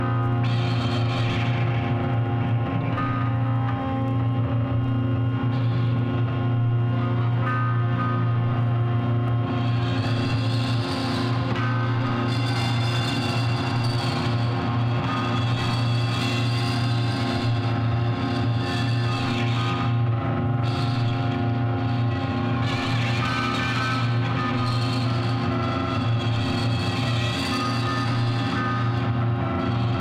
guitarras, baixo, percussões